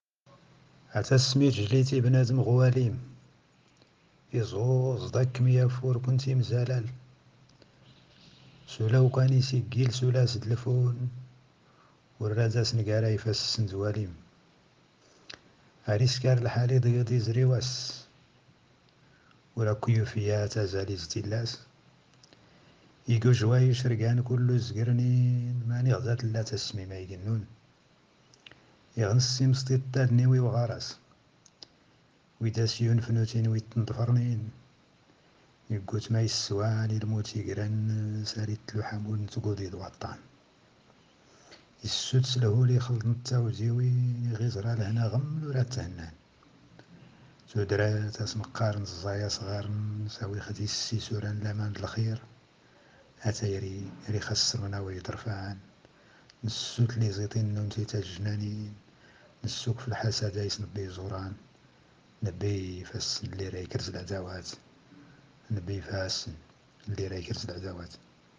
تامديازت/شعر